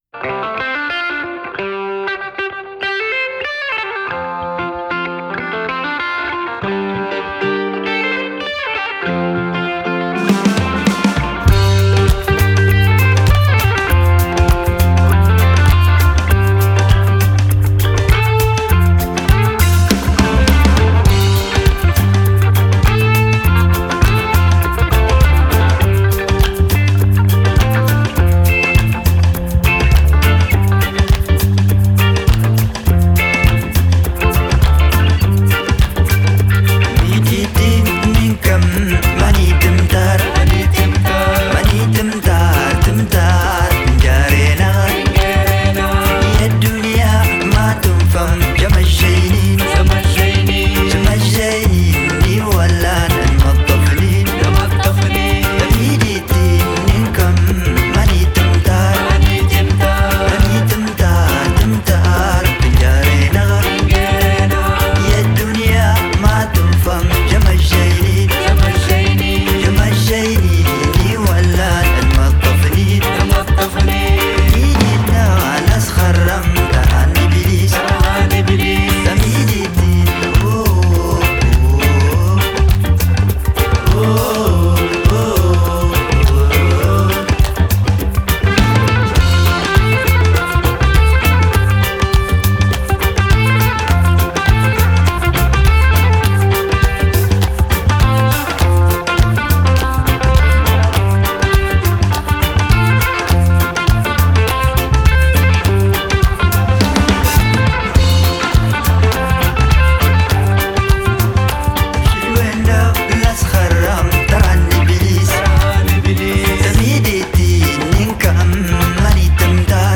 Genre: Desert Blues, Folk, World